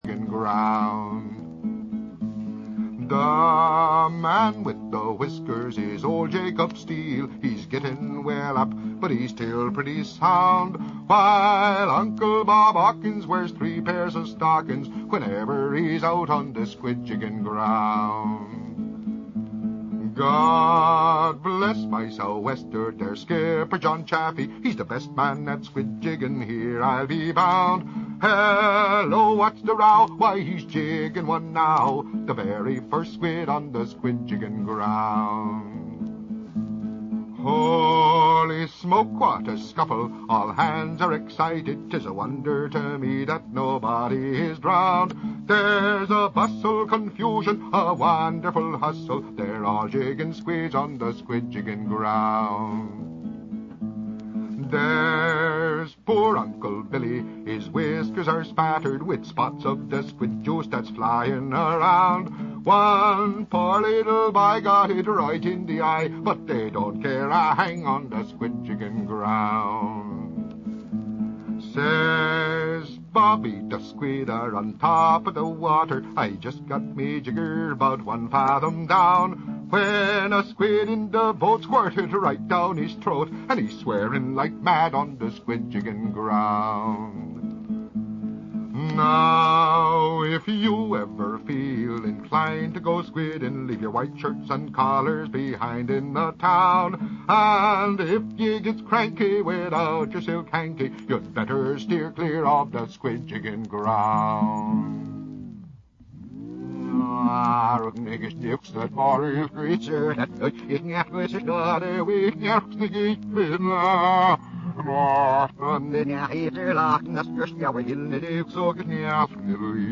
Live show every Thursday at 3 p.m. from Squidco is...